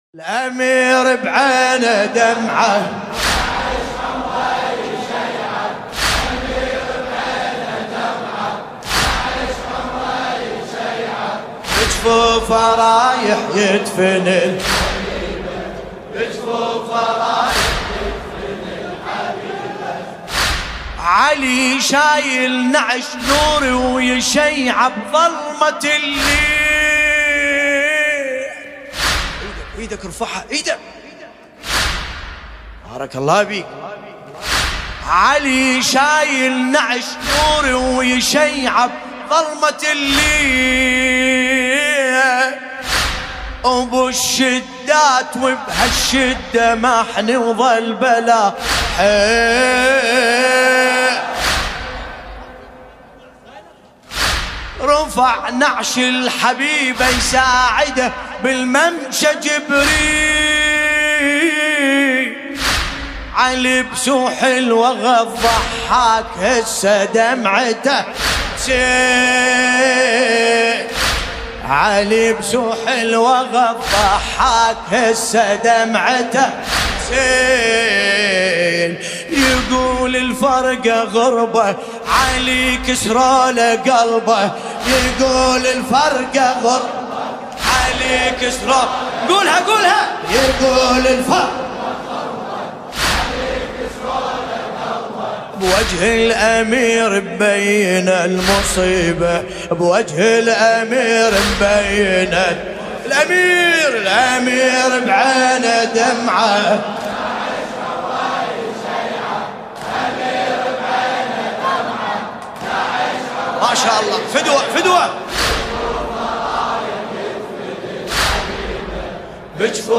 ملف صوتی الامیر بعینة دمعة بصوت باسم الكربلائي
الرادود : الحاج ملا باسم الكربلائي المناسبة : الليالي الفاطمية 1440جامع الحاج علي باش - بغداد الكريعات